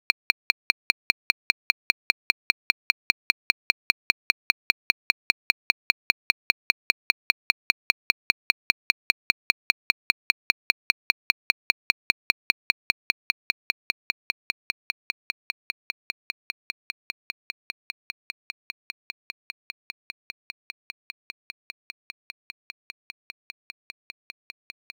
所以我做了一个小实验：我没有录制手表滴答声，而是用一个简单的物理模型生成了一个。
一个阻尼摆轮（游丝+惯性），在每次过零点时受到擒纵机构的脉冲推动，同时一个抽象的“发条能量”向空耗尽。当能量耗尽时，脉冲停止，振荡就归于寂静。
这是25秒模拟杠杆擒纵机构的滴答声，随着“发条盒”的耗尽而逐渐消失：
如果你想单独获取文件：watch_decay.wav
逐渐的损失。软化。你意识到系统仍在运行，但它是在透支能量的情况下运行的那一刻。
"""短促的共振咔哒声+噪声（程序化，无采样）。"""